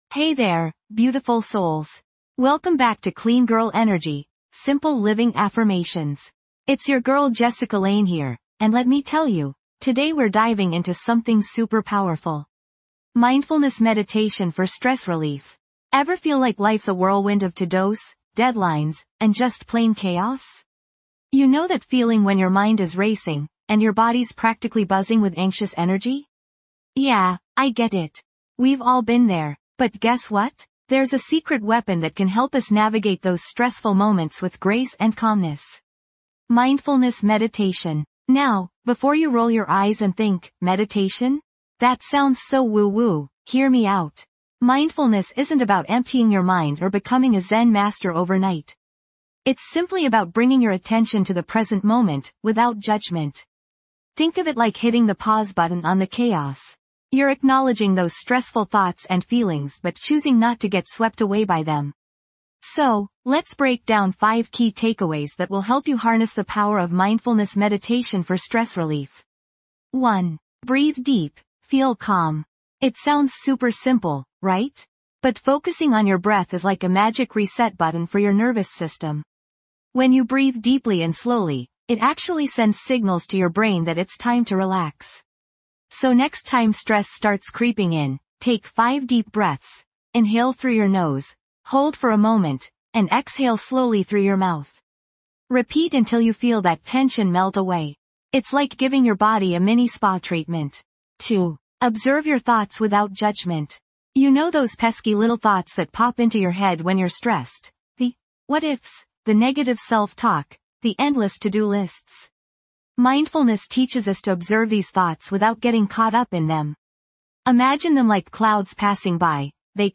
Episode Description:. Dive deep into a soothing session of Mindfulness Meditation designed to alleviate stress in this empowering episode of Clean Girl Energy Simple Living Affirmations.
This podcast is created with the help of advanced AI to deliver thoughtful affirmations and positive messages just for you.